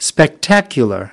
10. spectacular (adj) /spekˈtækjələr/: đẹp mắt, ngoạn mục